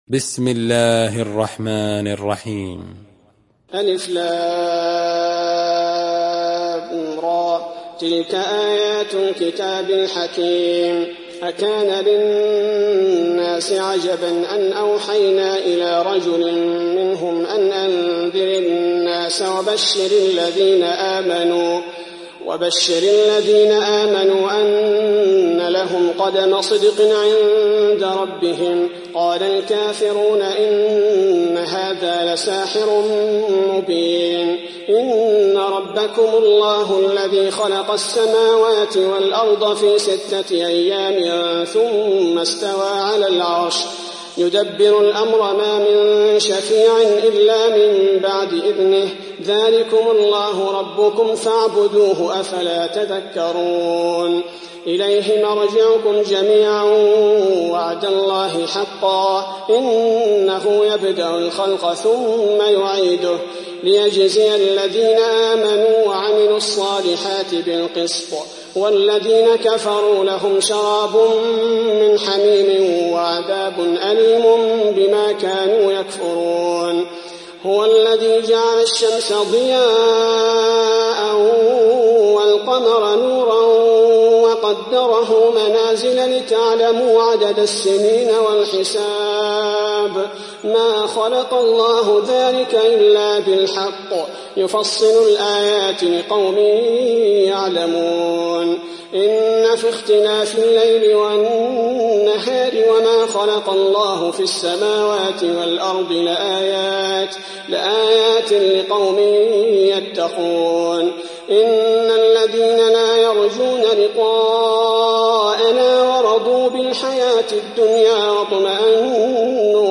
دانلود سوره يونس mp3 عبد الباري الثبيتي روایت حفص از عاصم, قرآن را دانلود کنید و گوش کن mp3 ، لینک مستقیم کامل